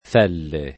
f$le] e, più rara, felle [
f$lle]: Lascio lo fele [l#ššo lo f$le] (Dante); quella che più ha di felle [kU%lla ke ppLu # ddi f$lle] (id.)